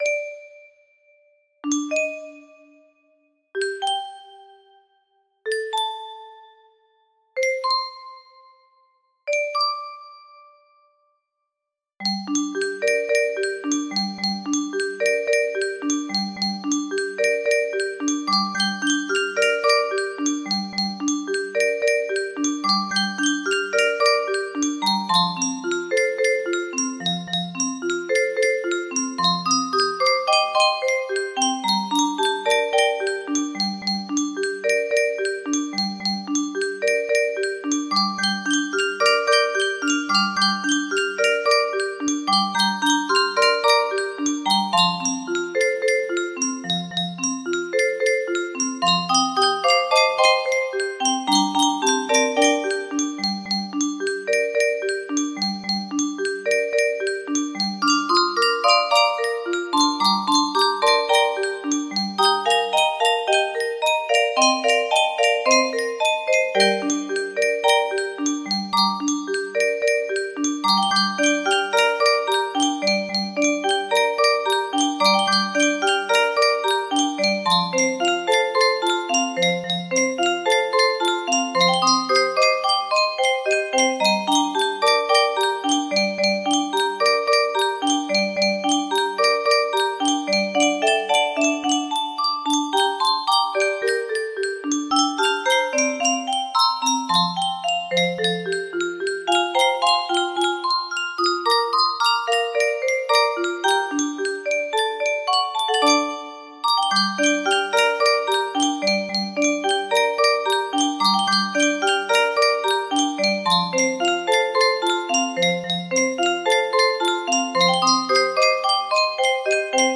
Grand Illusions 30 (F scale)
for Grand Illusion 30 (F scale) Music Boxes